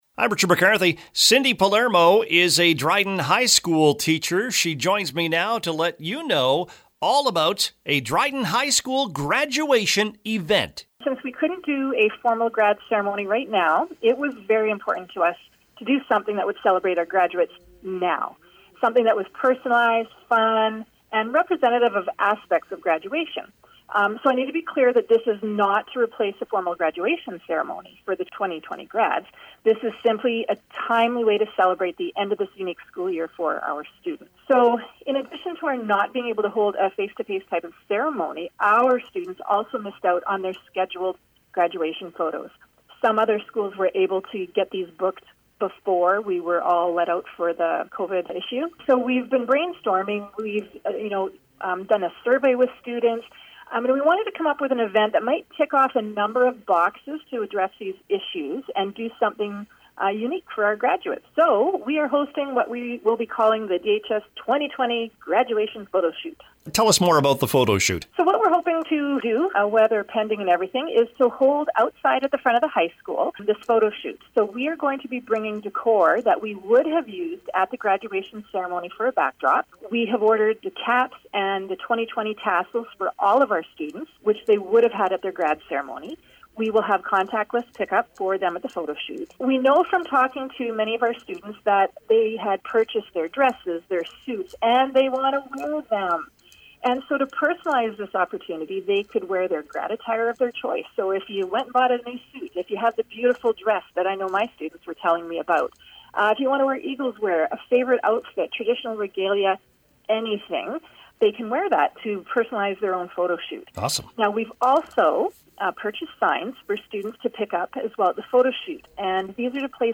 was a guest on the CKDR Morning Show Thursday